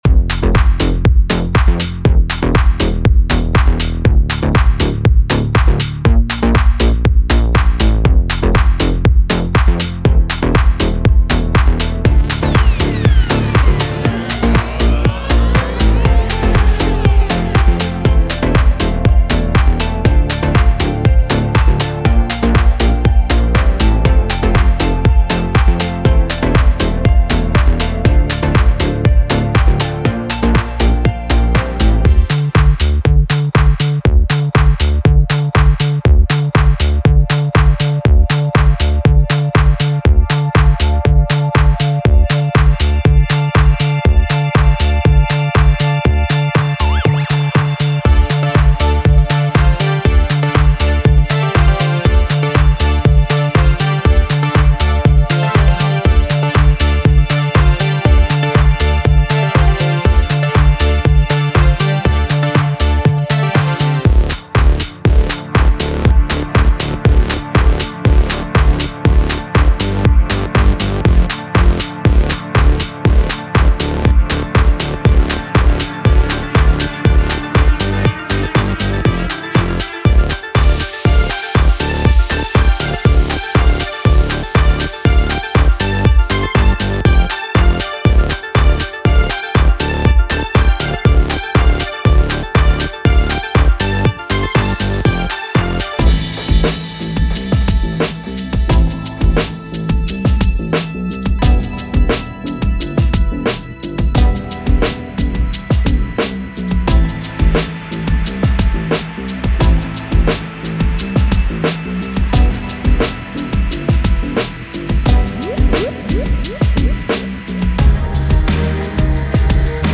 Multi-genre
of 75 patches that cover Bass, Leads, Fx, Pads and Synth you will be sure to find the right sound you need!